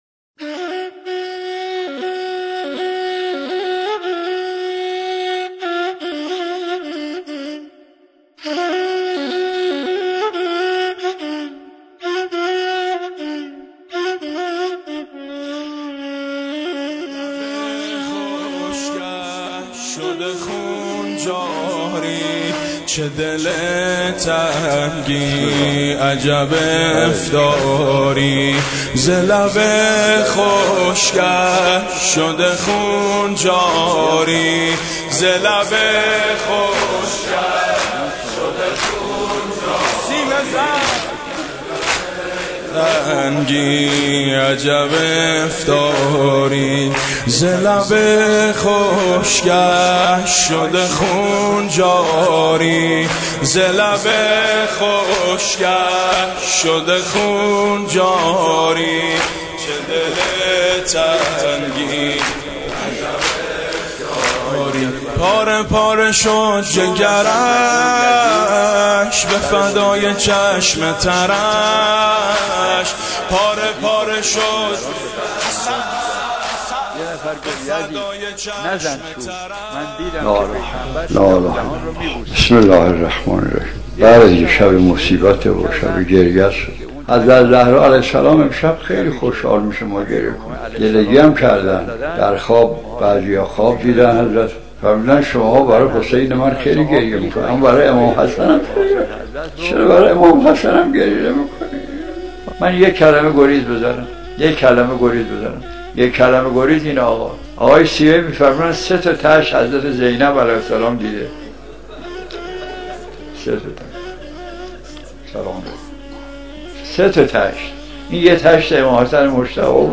گروه چندرسانه‌ای ــ بخشی از روضه‌خوانی آیت‌الله مجتهدی تهرانی(ره) را در عزای سبط اکبر امام حسن مجتبی(ع) می‌شنوید.
صوت/ روضه امام حسن مجتبی(ع)